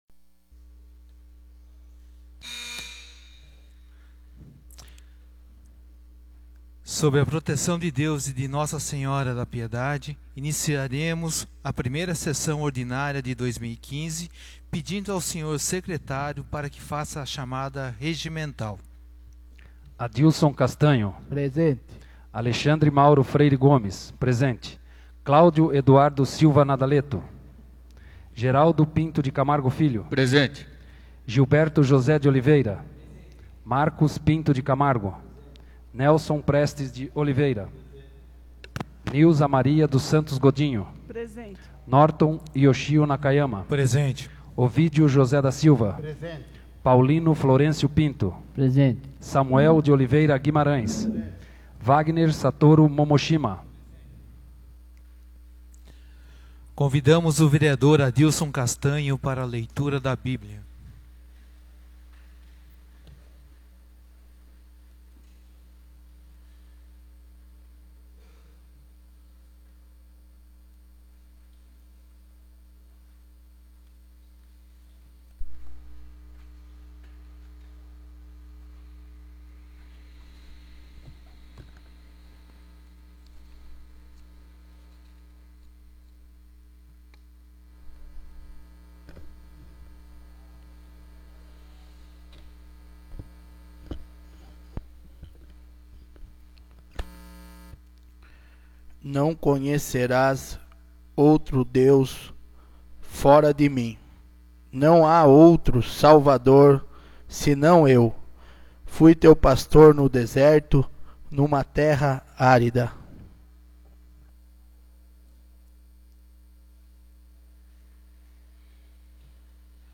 1ª Sessão Ordinária de 2015